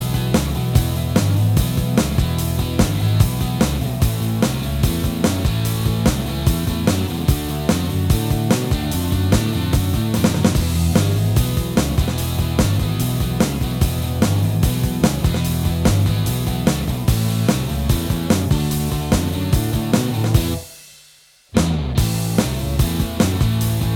Minus Guitars Soft Rock 2:50 Buy £1.50